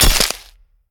box_glass_open-2.ogg